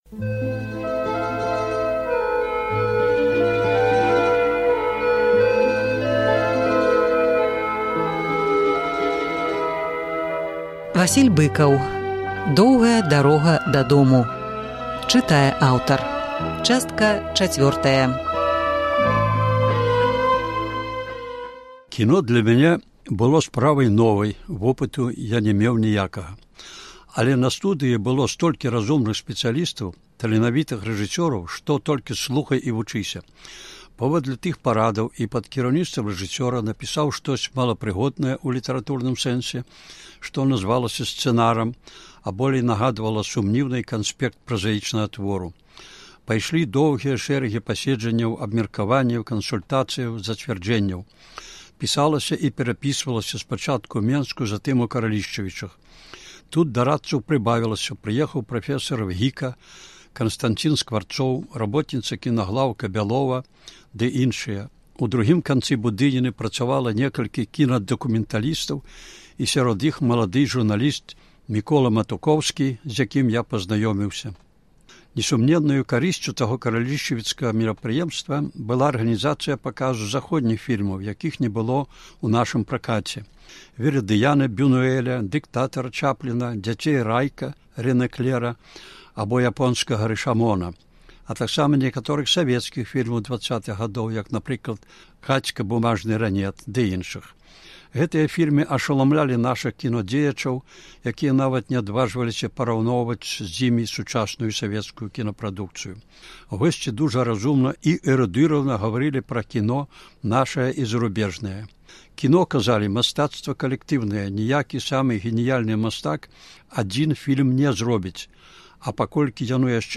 Успаміны Васіля Быкава «Доўгая дарога дадому». Чытае аўтар.